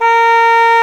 Index of /90_sSampleCDs/Roland L-CDX-03 Disk 1/WND_Bassoons/WND_Bassoon 3
WND C4  DB.wav